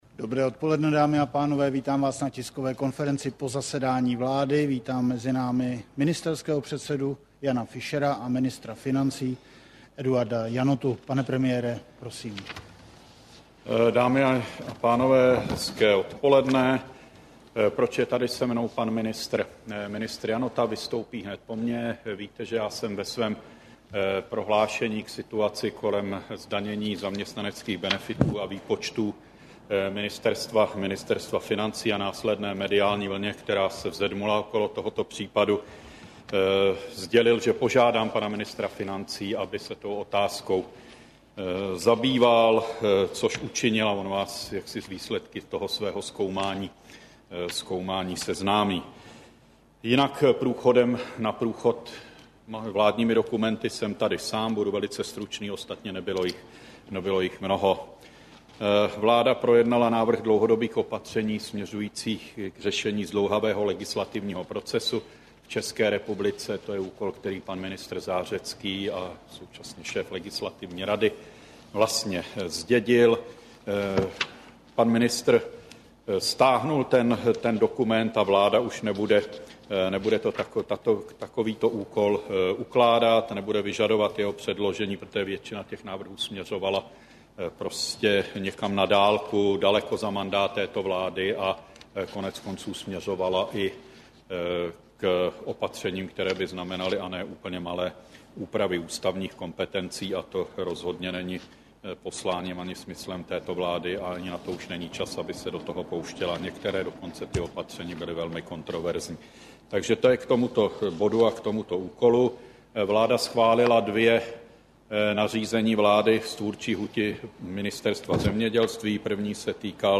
Tiskový brífink po jednání vlády, 8. března 2010